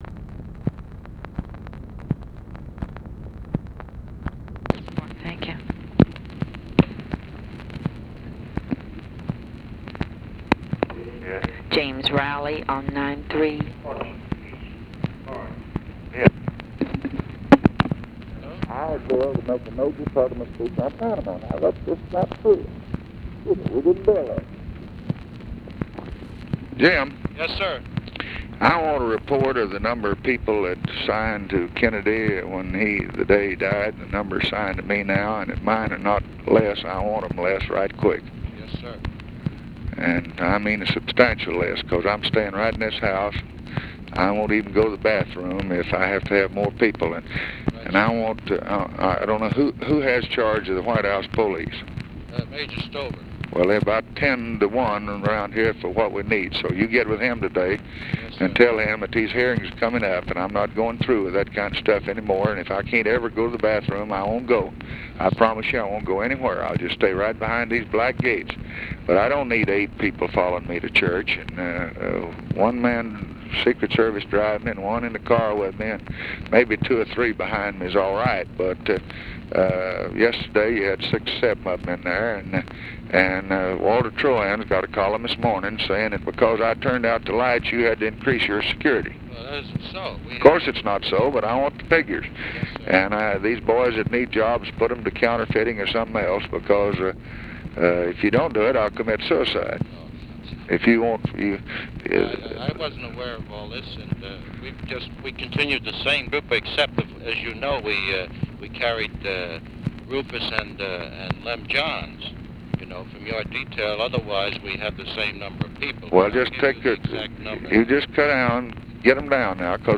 Conversation with JAMES ROWLEY, March 2, 1964
Secret White House Tapes